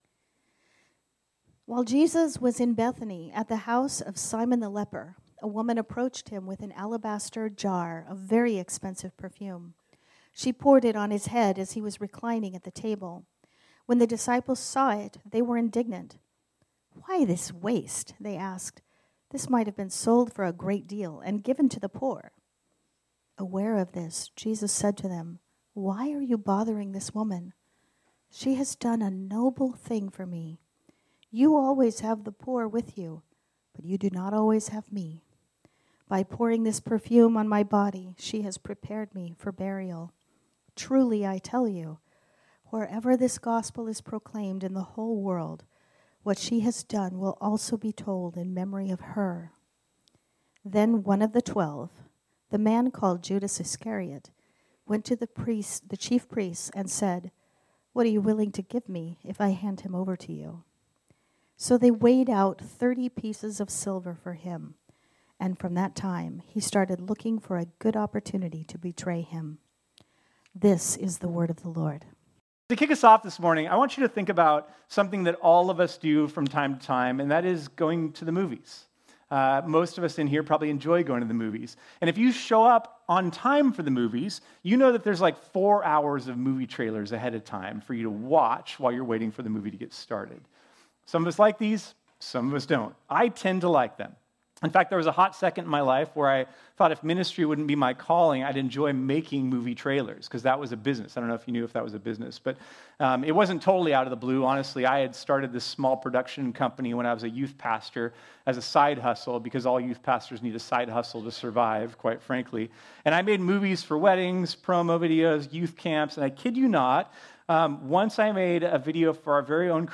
This sermon was originally preached on Sunday, November 3, 2024.